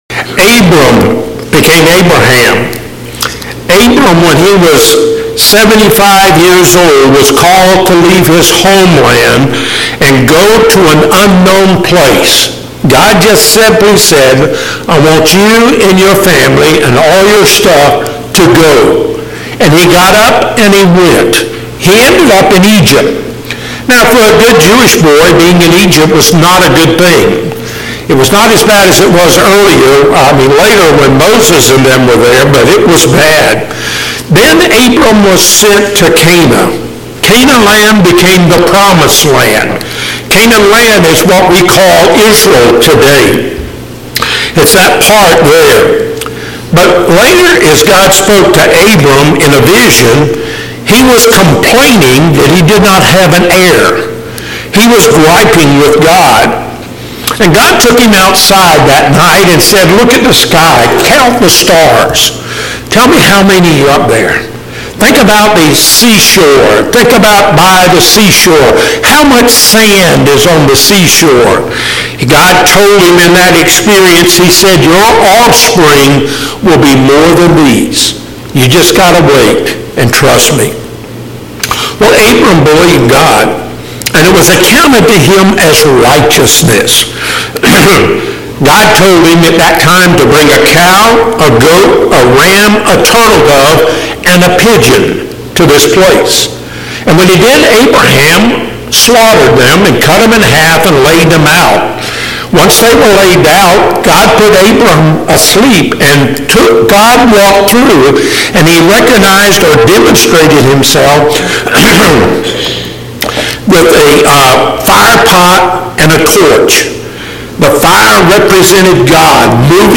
Passage: Genesis 22:1-19 Service Type: Sunday Morning Topics